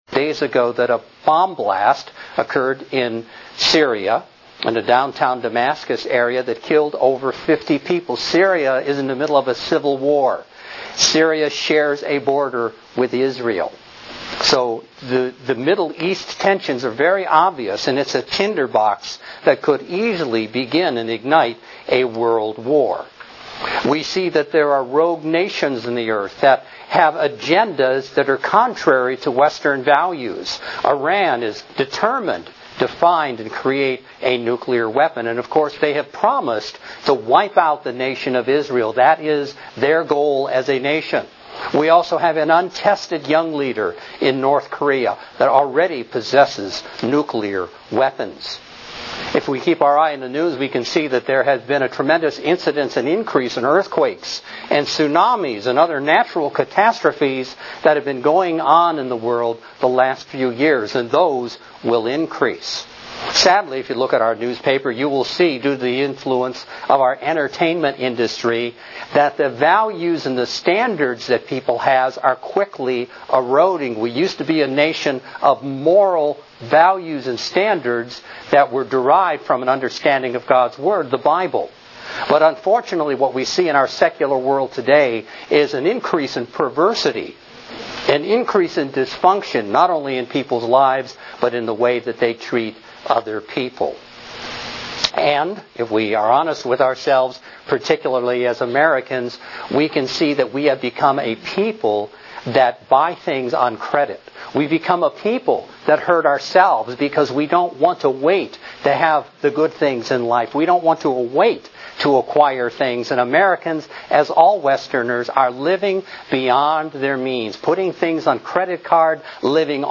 Mankind is headed toward self-destruction. This Kingdom of God seminar discusses mankind's only hope in this world, the coming Kingdom of God.